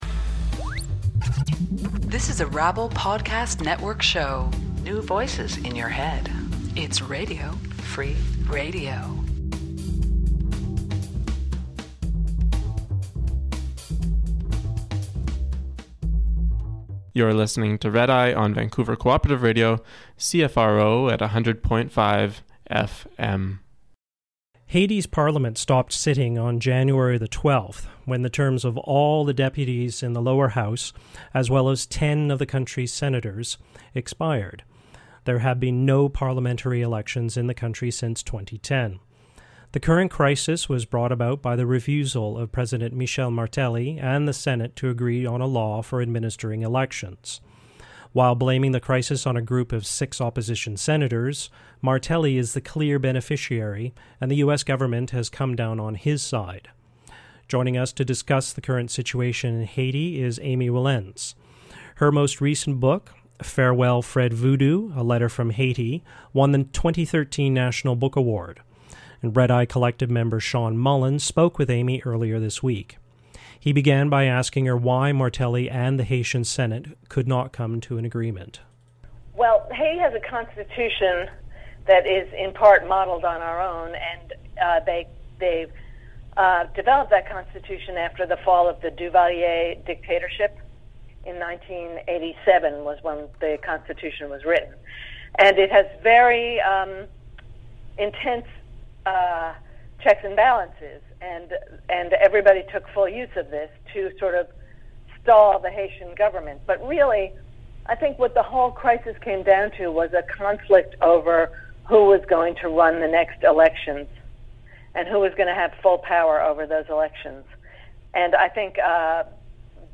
Genre: Interview